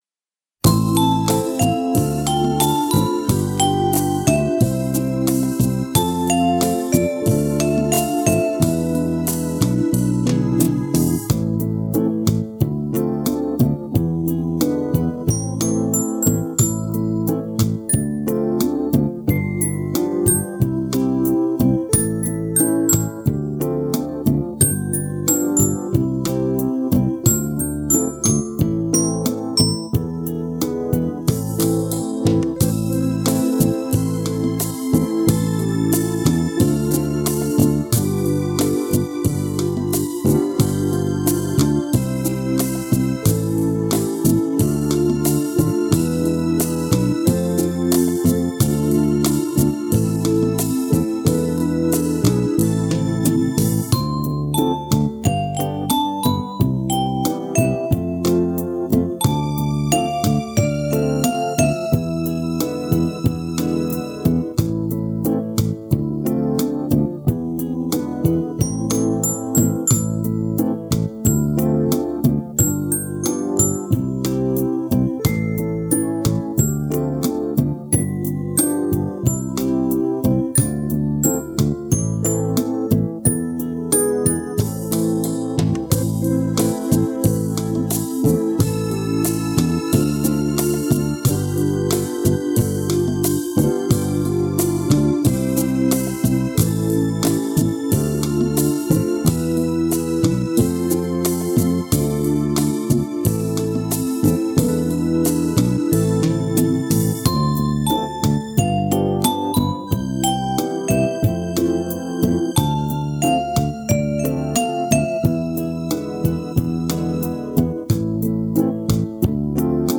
Rozhdestvenskij_sochelnik_-_minus_mp3.mn_.mp3